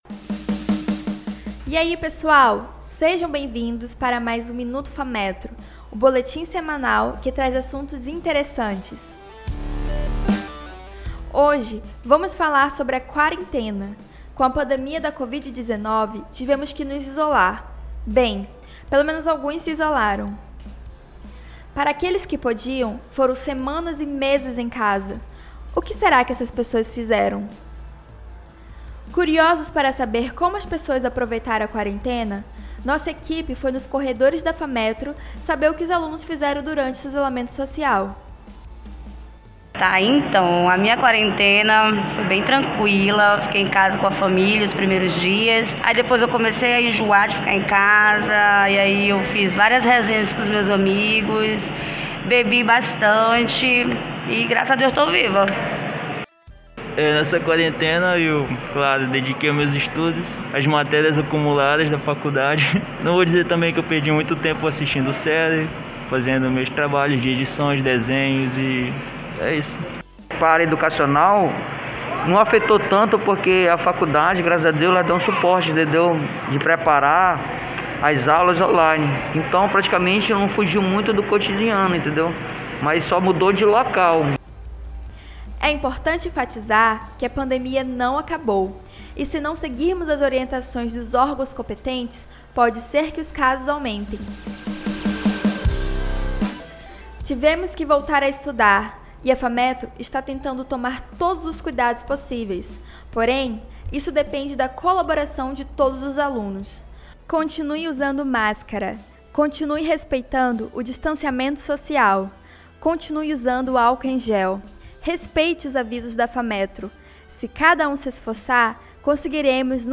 a edição de hoje trás relatos de alunos de diversos curso da Fametro sobre o isolamento social e muito mais.